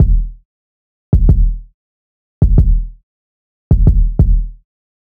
kick - prove (edit).wav